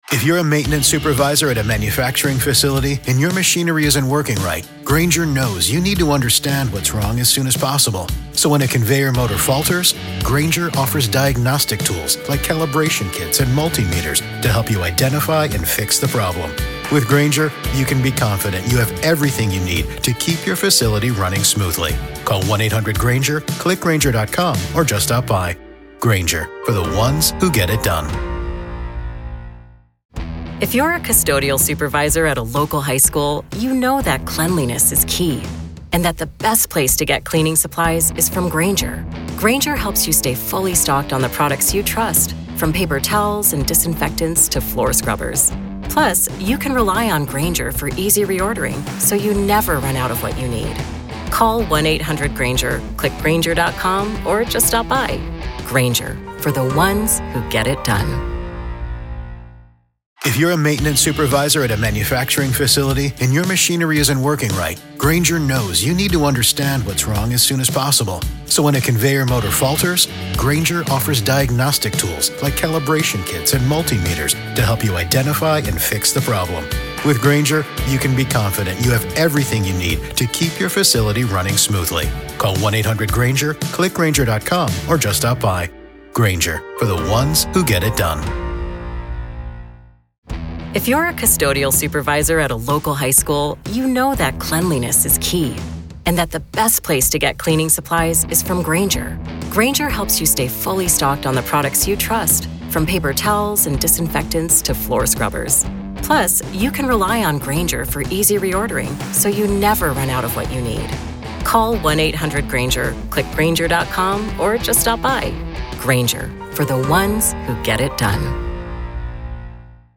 SOME LANGUAGE MAY BE OFFENSIVE:
Headliner Embed Embed code See more options Share Facebook X Subscribe SOME LANGUAGE MAY BE OFFENSIVE: Welcome to a special episode of "The Trial of Karen Read," where today, we find ourselves inside the courtroom of the case against Karen Read.